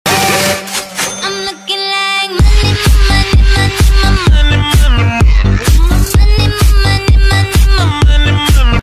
donate-song-alert_CKprPlg.mp3